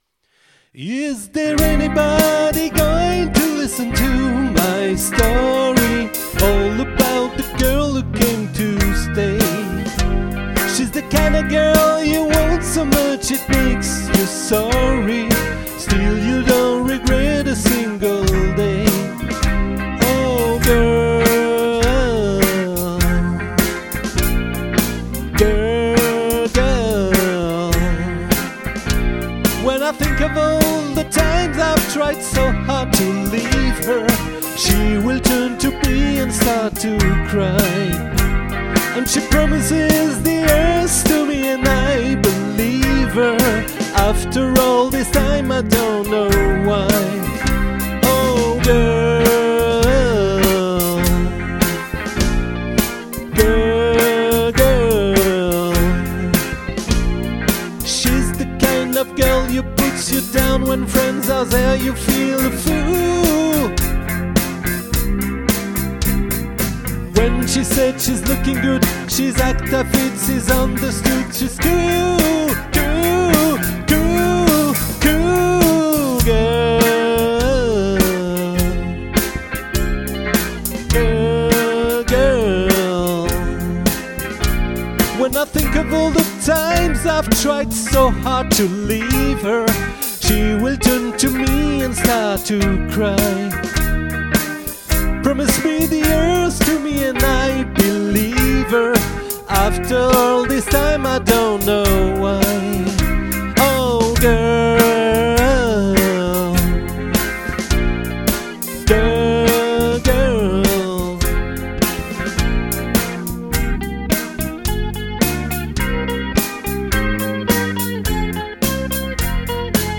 🏠 Accueil Repetitions Records_2024_08_29